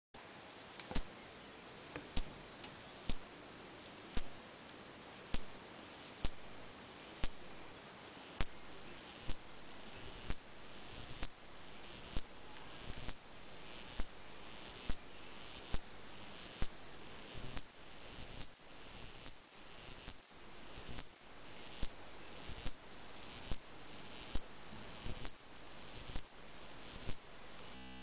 Cigale chanteuse Cicadetta cantilatrix